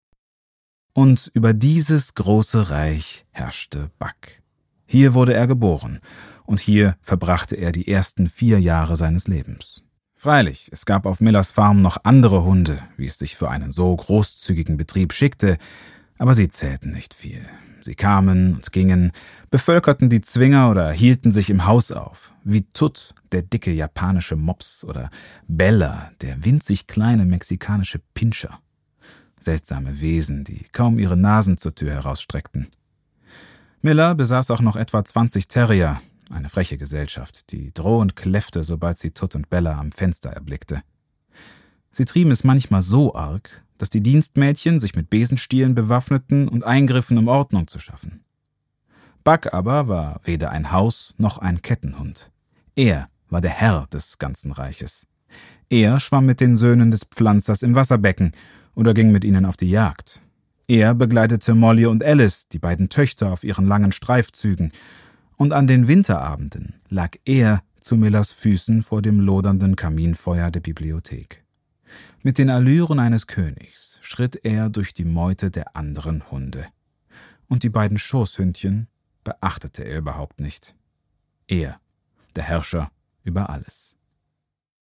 Hörbuch
Meine stimmcharakteristik ist warm, klar und nahbar.
1-Hoerbuch-Roman.mp3